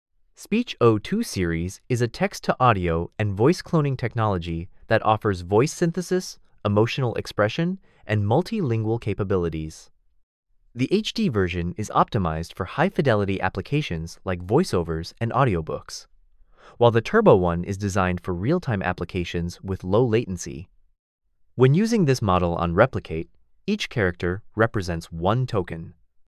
A high-fidelity text-to-speech model that generates natural audio output with precise control over vocal characteristics like emotion, language, pitch, and speed for professional applications.
"channel": "mono",
"emotion": "happy",
"voice_id": "Friendly_Person",